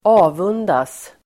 Uttal: [²'a:vun:das]